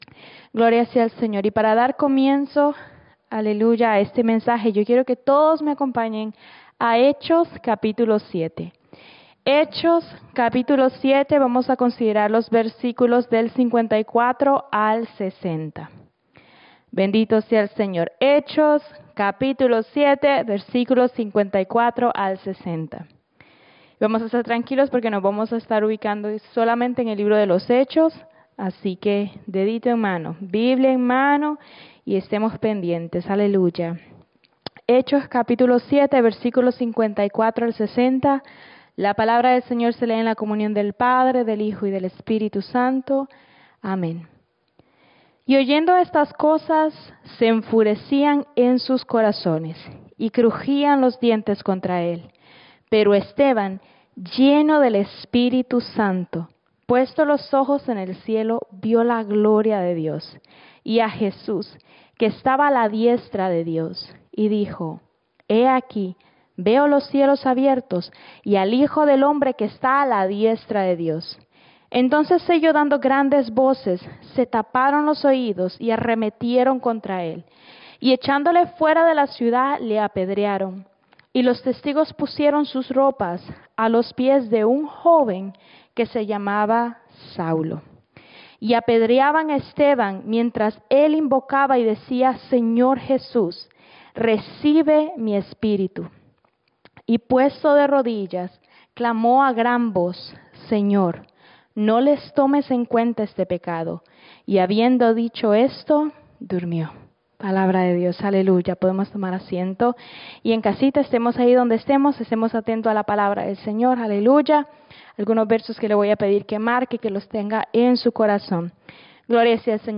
Mensaje
Iglesia Misión Evangélica en Souderton, PA